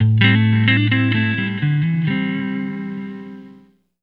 Index of /90_sSampleCDs/Zero-G - Total Drum Bass/Instruments - 2/track43 (Guitars)
05 Little Wings Amin.wav